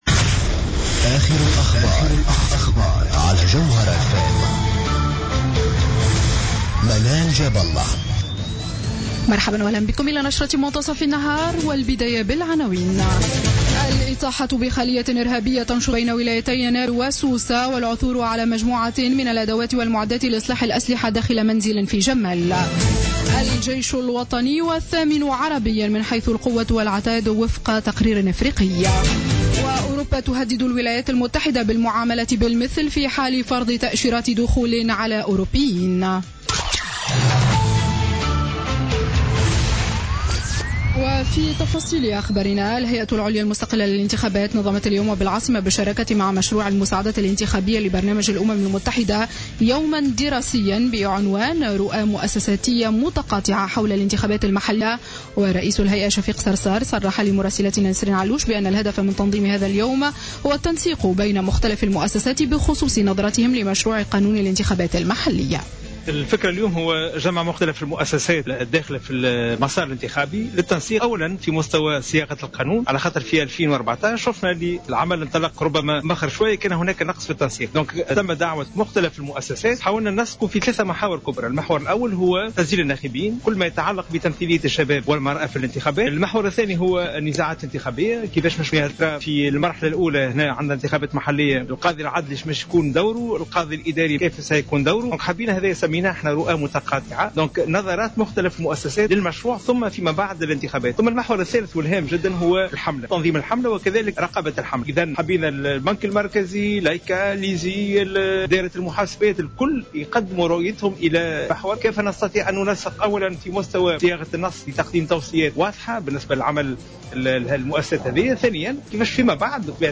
نشرة أخبار منتصف النهار ليوم الثلاثاء 15 ديسمبر 2015